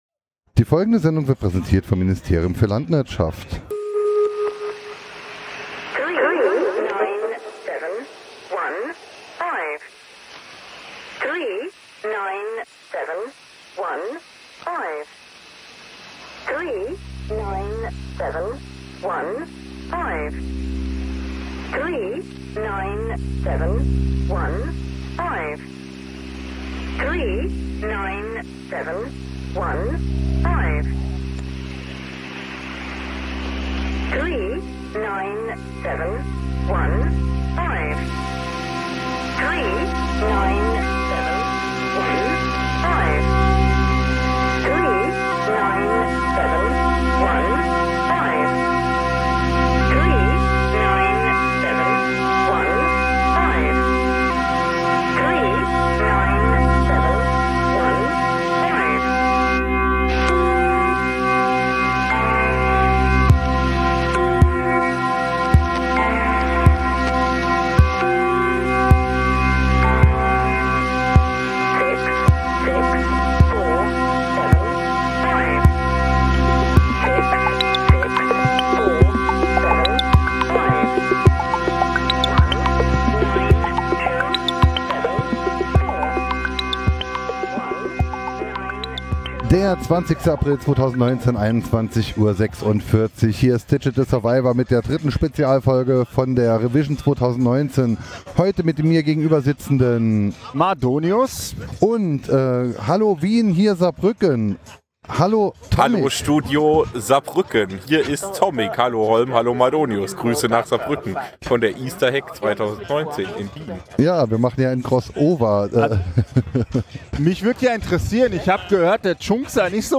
Technikpodcast.